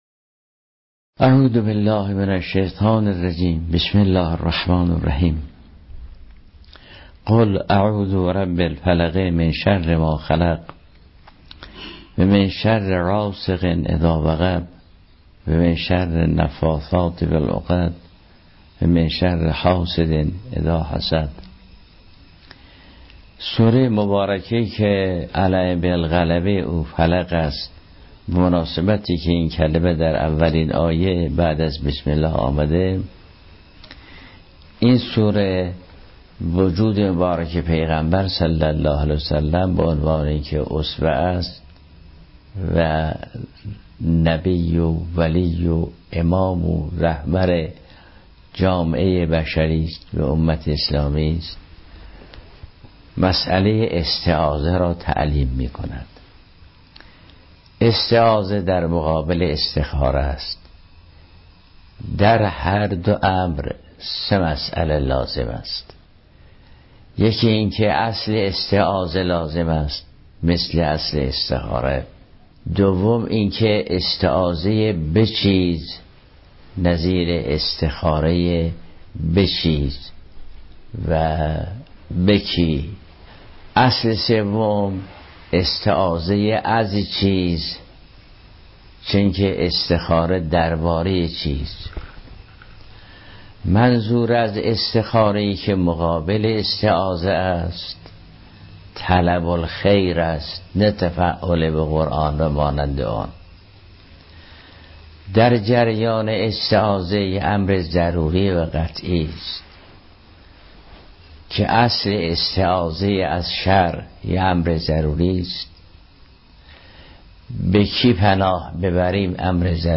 در سالروز میلاد کریمه اهل بیت فاطمه معصومه سلام الله علیها، و در بیست و سومین مجمع عمومی نشست دوره ای اساتید سطوح عالیه و خارج حوزه علمیه قم، حضرت آیت الله العظمی جوادی آملی، در جمع صدها تن از اساتید به ایراد سخن پرداختند.